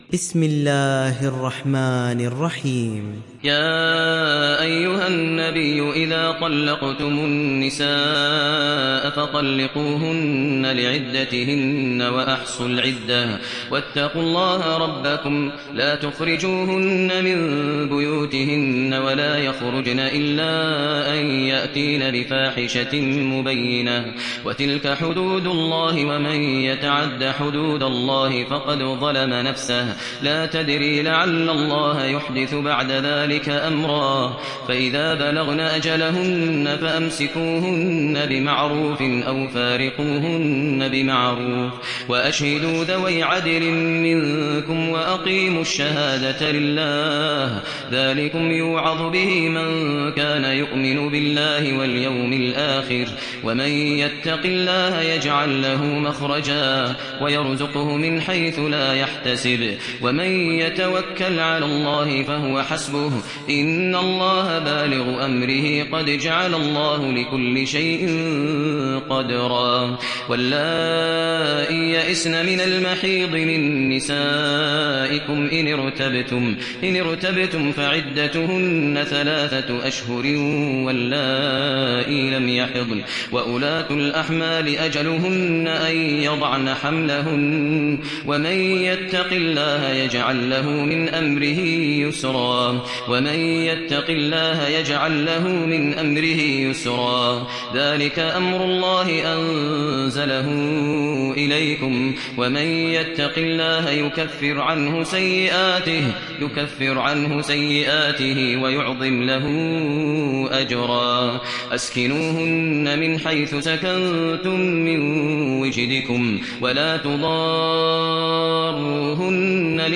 Surat At Talaq mp3 Download Maher Al Muaiqly (Riwayat Hafs)